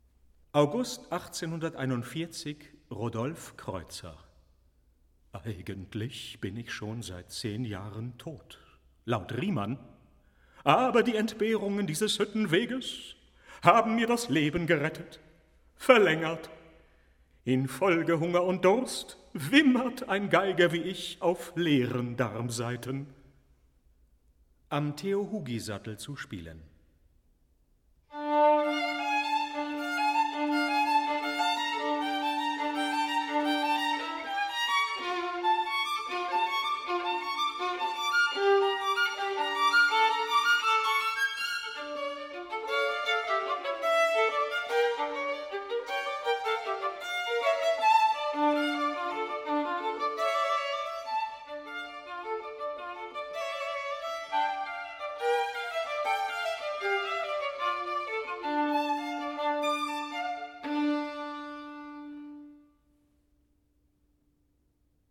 Albert Moeschinger: Rodolphe Kreutzer, 1841 (violin)